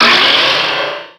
Cri de Méga-Ténéfix dans Pokémon Rubis Oméga et Saphir Alpha.
Cri_0302_Méga_ROSA.ogg